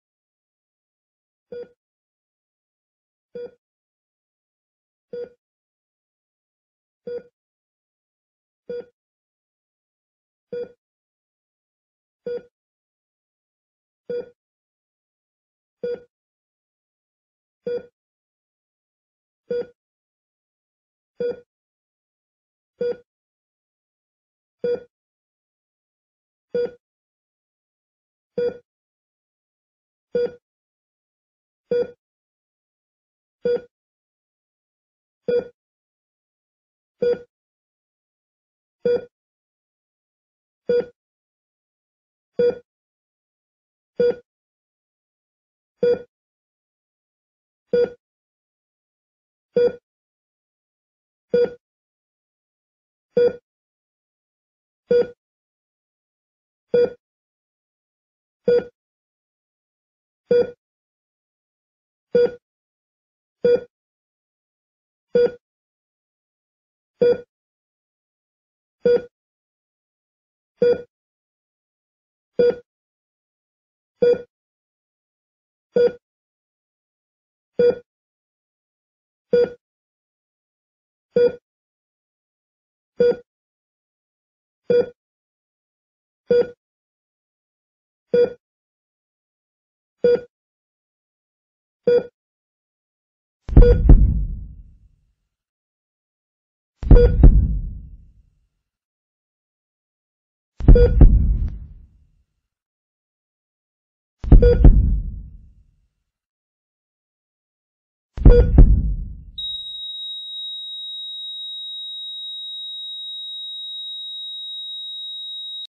Heartbeat Flatline Monitoring